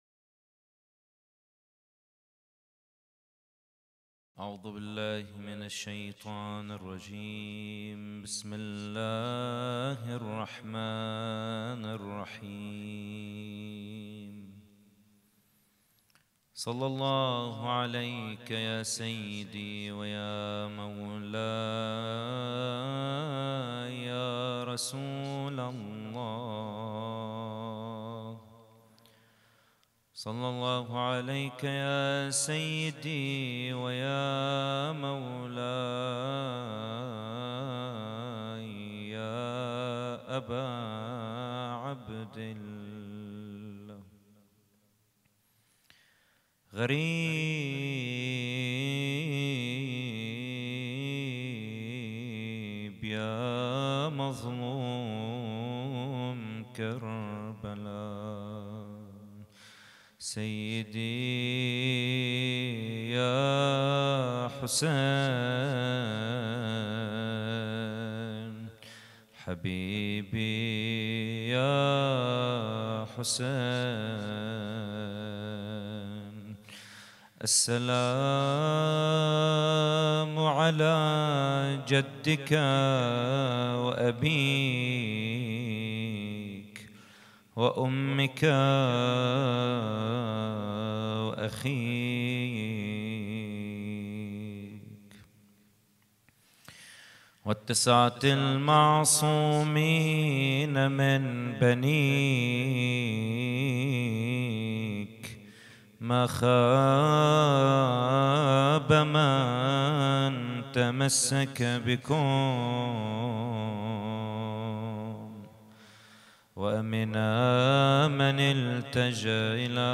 محاضرة
إحياء الليلة التاسعة من محرم 1442 ه.ق